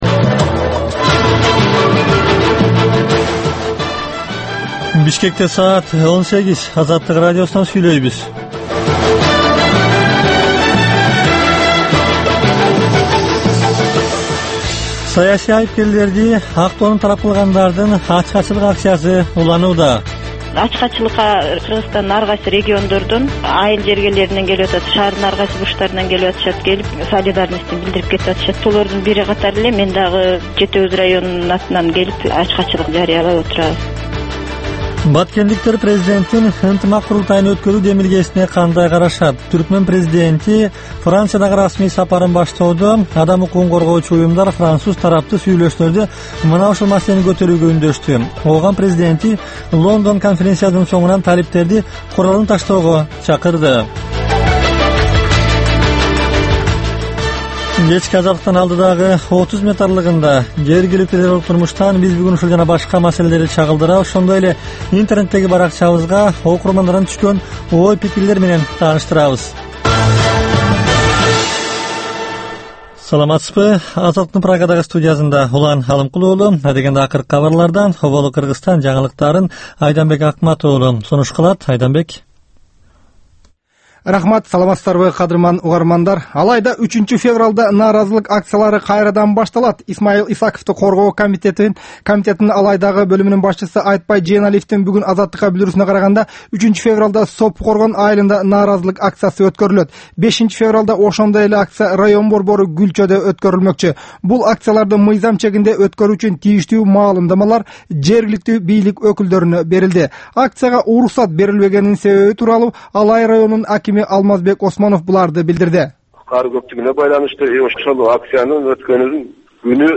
"Азаттык үналгысынын" бул кечки алгачкы берүүсү жергиликтүү жана эл аралык кабарлардан, репортаж, маек, баян жана башка берүүлөрдөн турат. Бул үналгы берүү ар күнү Бишкек убактысы боюнча саат 18:00ден 18:30га чейин обого түз чыгат.